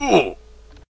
classic_hurt.ogg